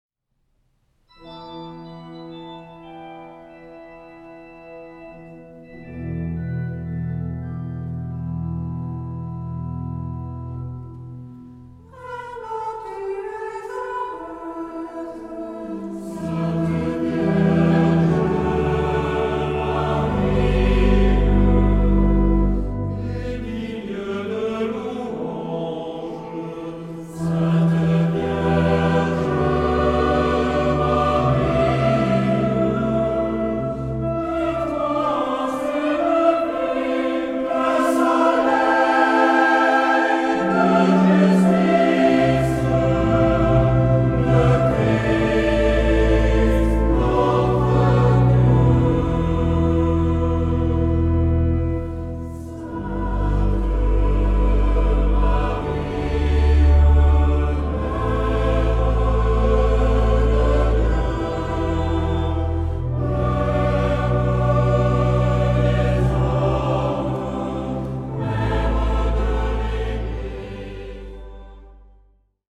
Genre-Stil-Form: Chor ; geistlich
Chorgattung: SATB  (4 gemischter Chor Stimmen )
Instrumente: Orgel (1)
Tonart(en): G-Dur ; e-moll